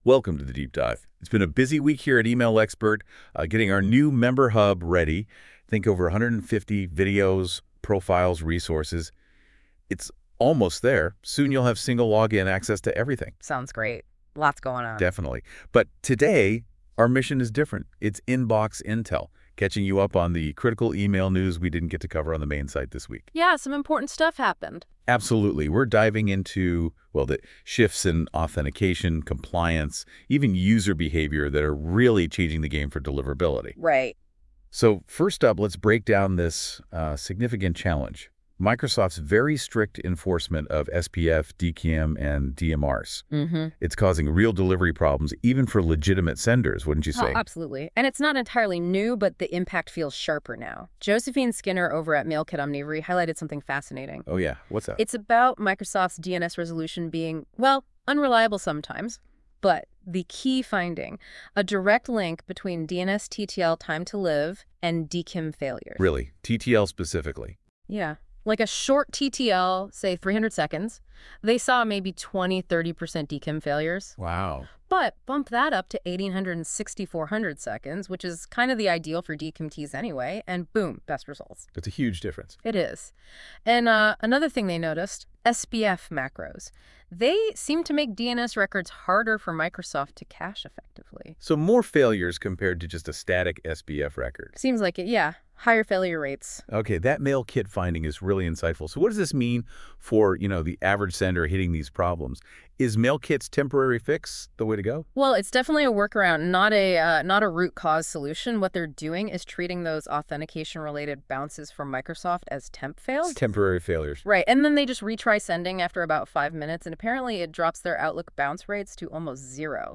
Powered by Notebook LLM and delivered by the emailexpert team, we share our latest projects, behind-the-scenes insights, and honest takes on where email marketing, deliverability, and infrastructure are headed. Each episode combines updates on what we're building and thinking about at emailexpert with sharp analysis of industry news, community buzz, tech shifts, and event coverage.